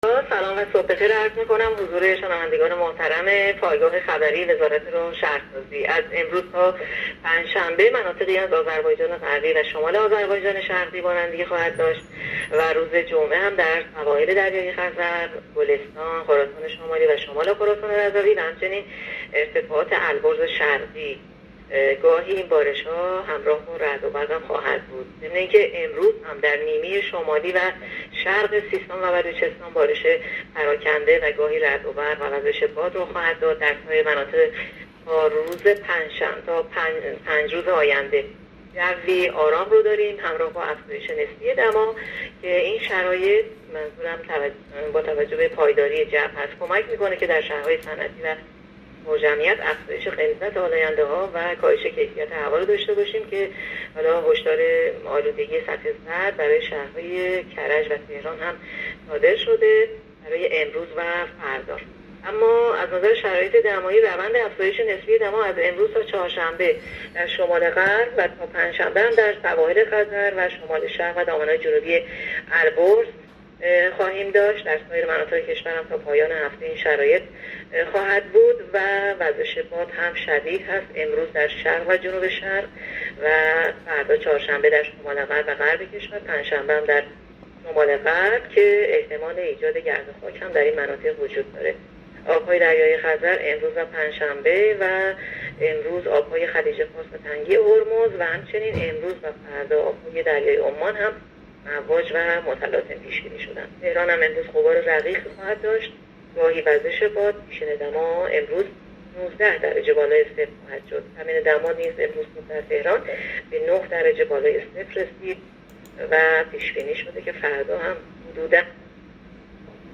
گزارش رادیو اینترنتی از آخرین وضعیت آب و هوای ۲۸ بهمن؛